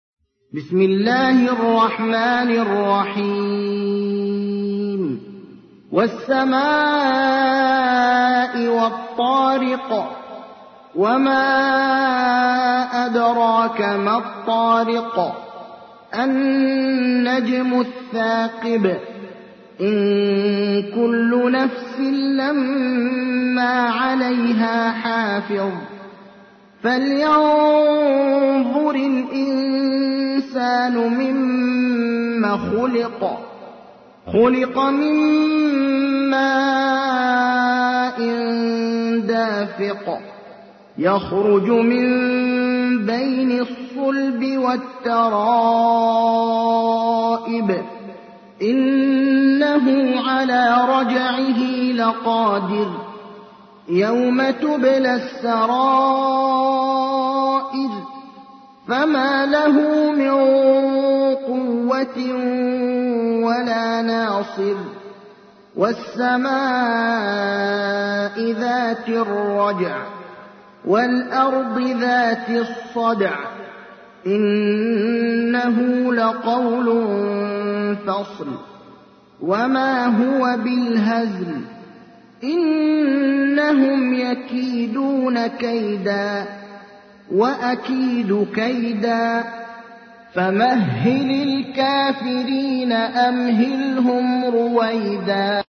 تحميل : 86. سورة الطارق / القارئ ابراهيم الأخضر / القرآن الكريم / موقع يا حسين